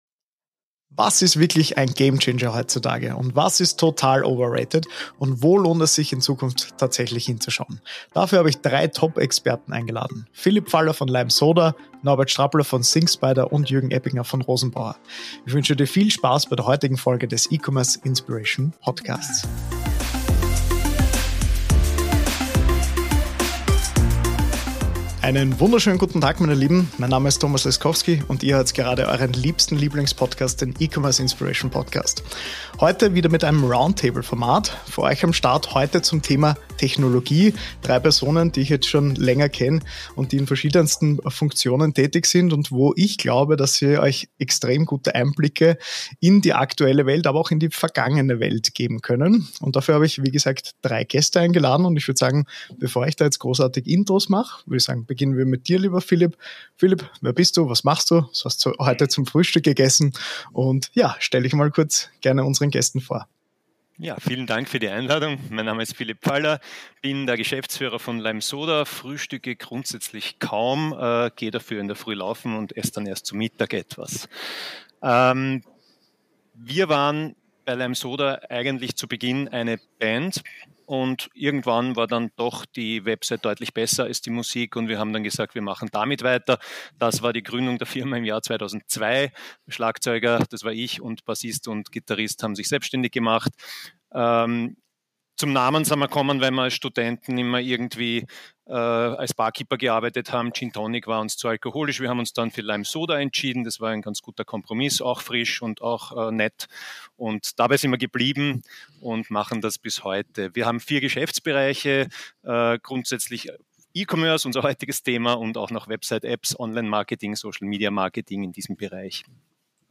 E-Commerce-Technologien entwickeln sich rasant – aber was bringt wirklich etwas und was ist nur ein Hype? In dieser Folge des E-Commerce Inspiration Podcasts diskutiere ich mit drei Experten über die aktuellen Trends: Was sind echte Game Changer?